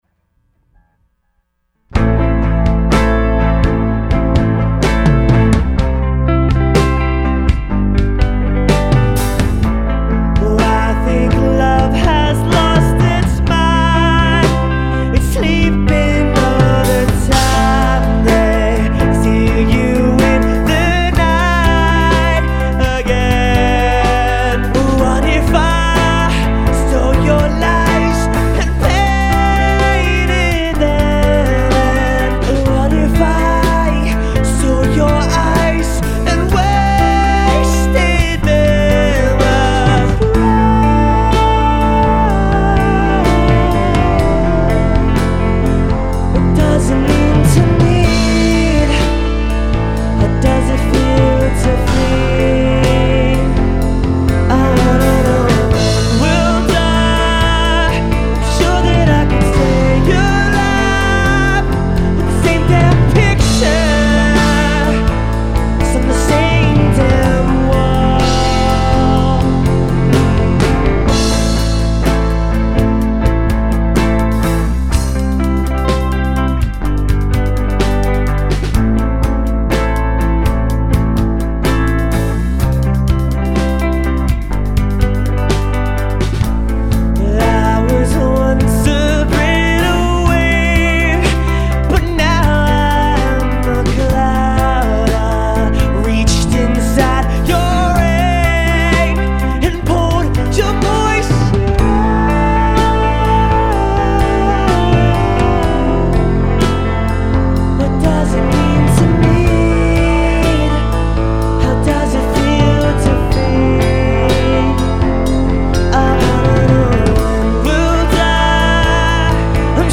My first track with a new singer.
BTW, the beginingand ending prolly need to be cleaned up, that much i know.